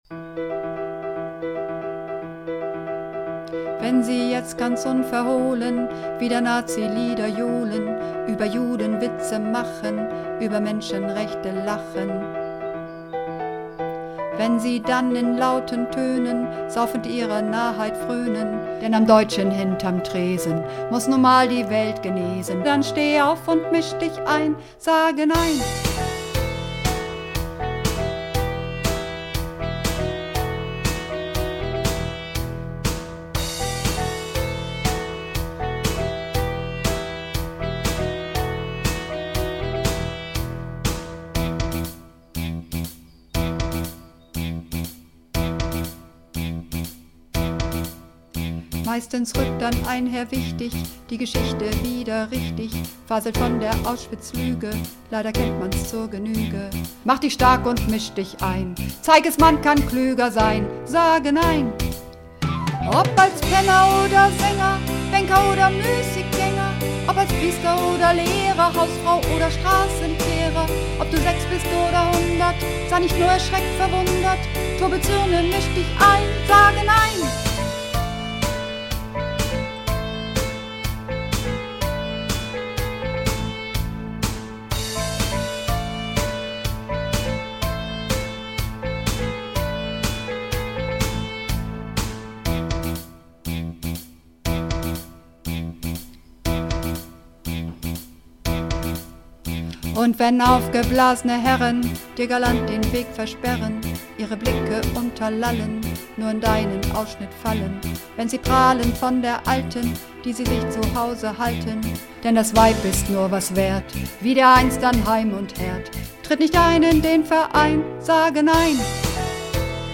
Übungsaufnahmen
Sage Nein (Sopran)
Sage_Nein__3_Sopran.mp3